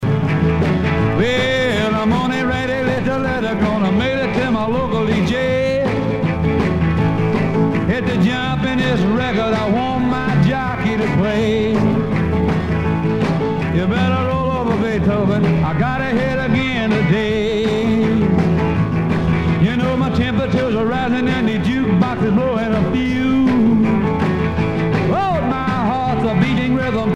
danse : rock